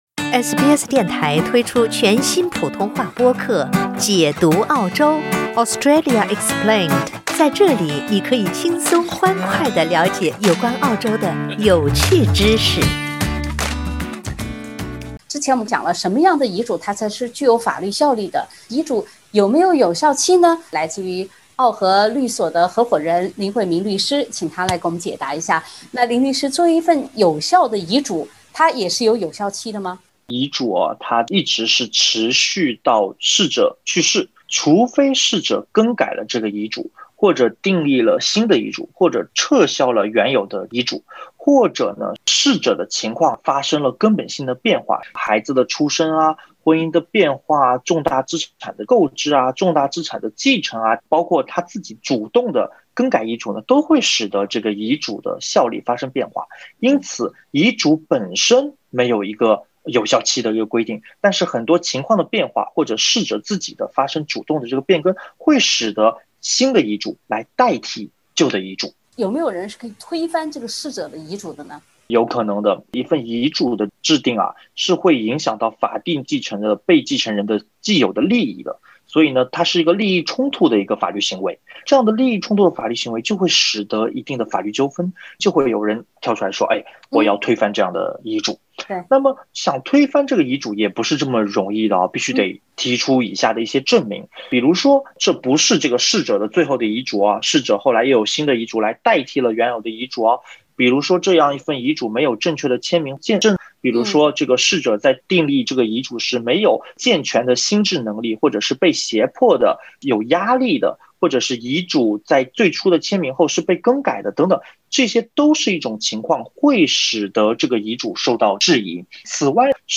已经立好的遗嘱也可能被一些人所推翻。（点击封面图片，收听完整采访）